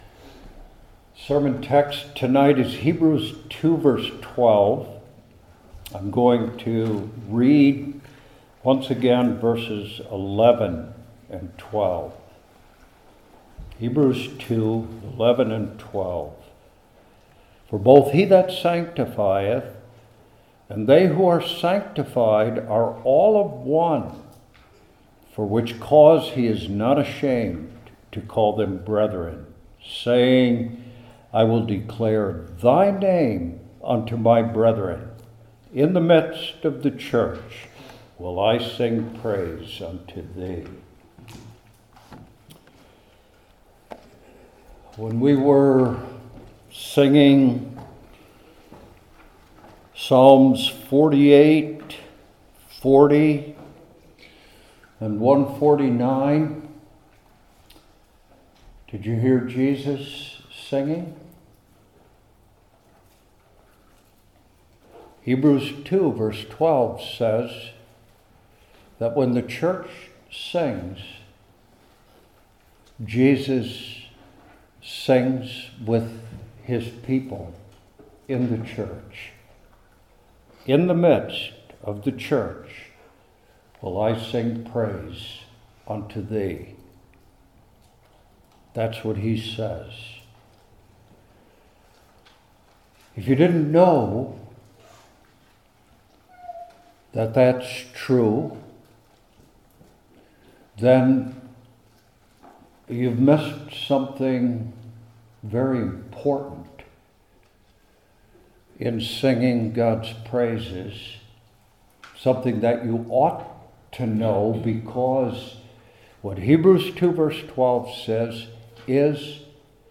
New Testament Individual Sermons I. How?